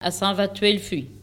Sallertaine ( Plus d'informations sur Wikipedia ) Vendée
Locutions vernaculaires